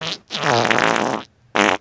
Catégorie Effets Sonores